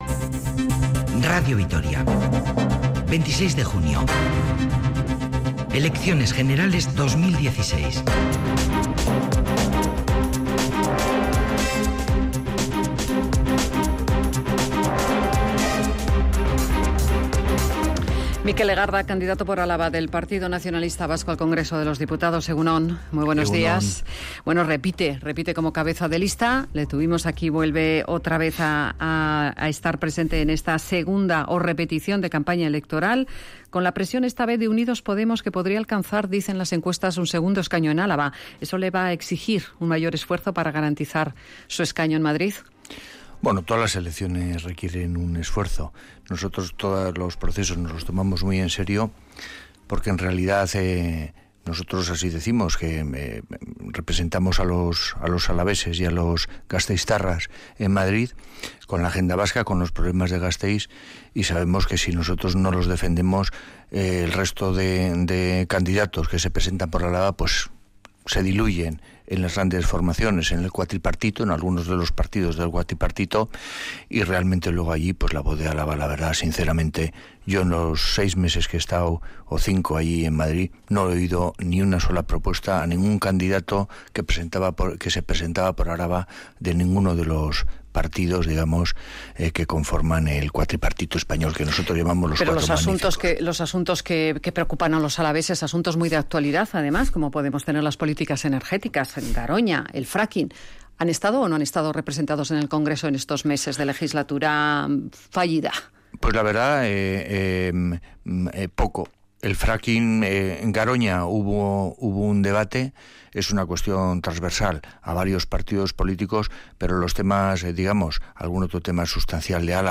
Radio Vitoria Entrevista de campaña electoral con el cabeza de lista del PNV por Álava al Congreso de los Diputados, Mikel Legarda.